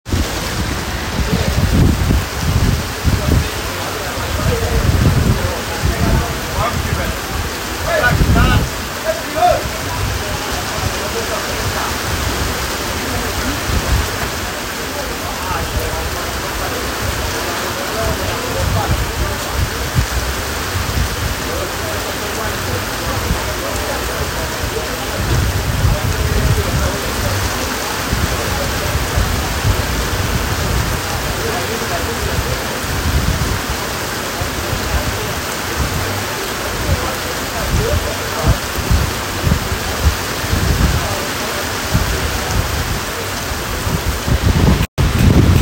Guatape Biblical Rain
While on a boat trip around Guatape Lake ( a man made reservoir), we got caught in torrential rain, my first experience of the torrential rain in Colombia.
guatape_lake_rain.m4a